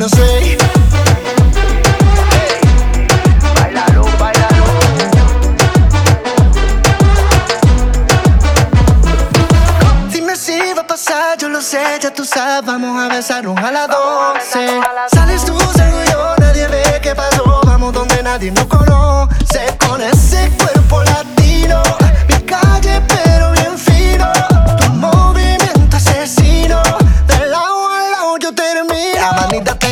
Genre: Pop Latino